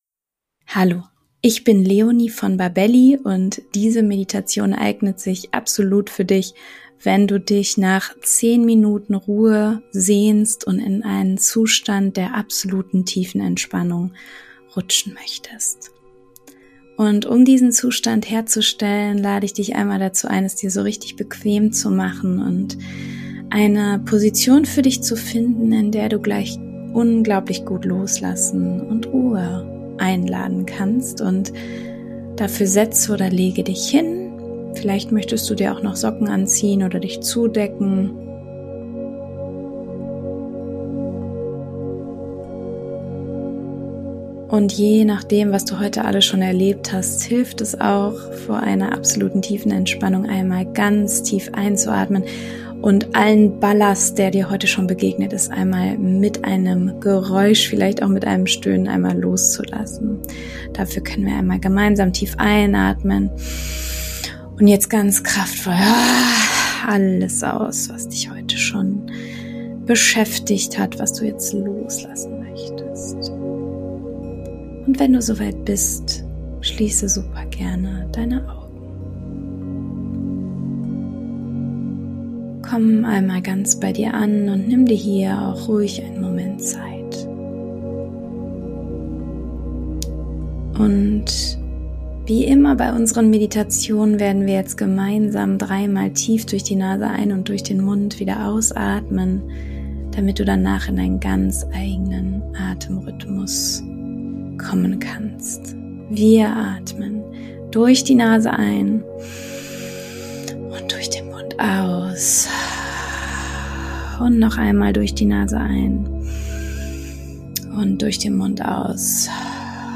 Dann probiere doch gerne mal diese Meditation aus, die dich in nur 10 Minuten zu vollkommener, innerer Ruhe begleitet.